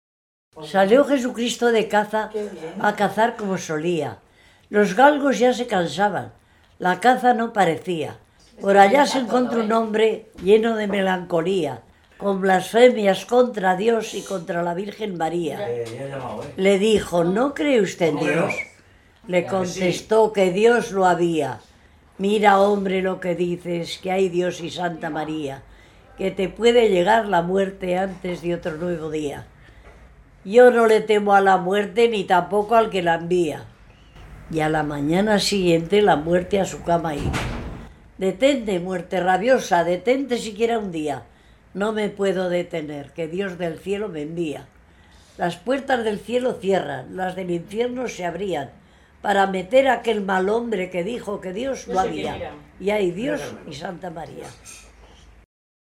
Clasificación: Romancero
Localidad: El Redal